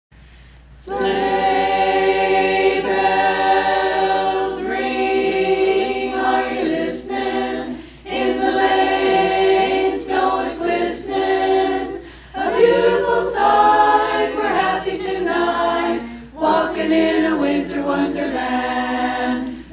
Simply A Cappella - Holiday Singing Grams